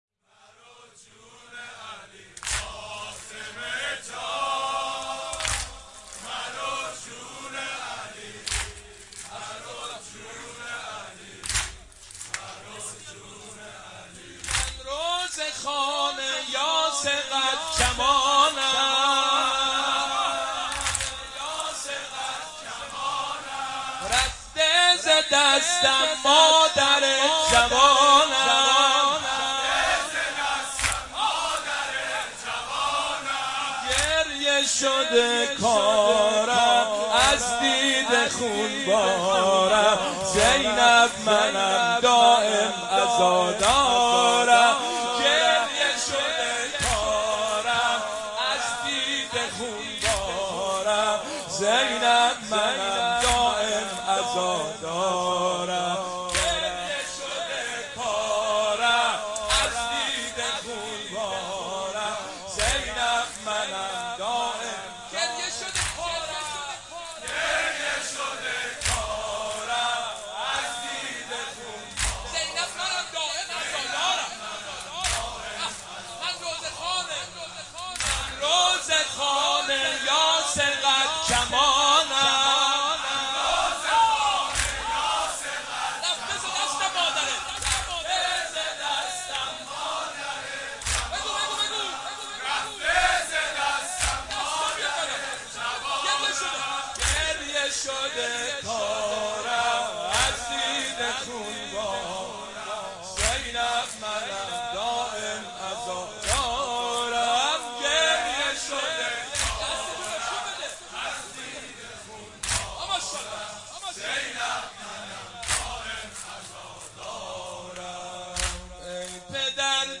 دانلود مداحی شهادت حضرت زهرا (س) ایام فاطمیه